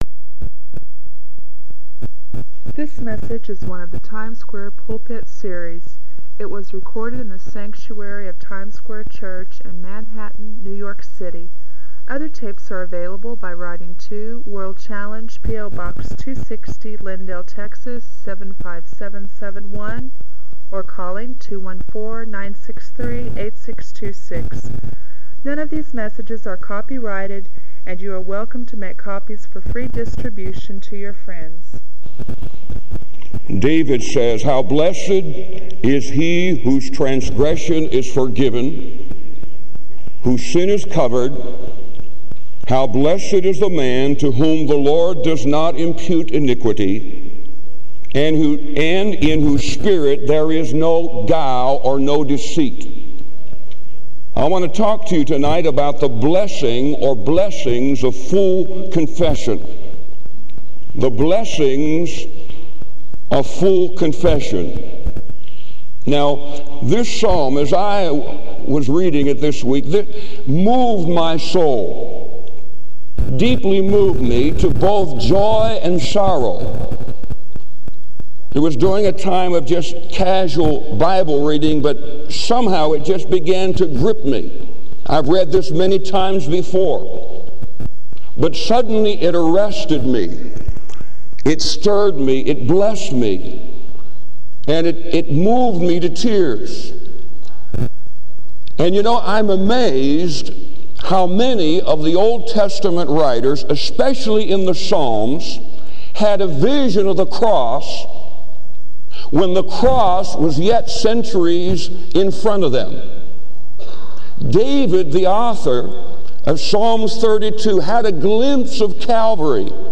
This sermon is a compelling call to live transparently and walk in the freedom Christ provides.